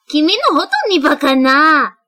描述：我是美国人，非常不会说日语。这些只是一些基本的短语。对不起任何发音问题。
标签： 说话 日本 声音 女孩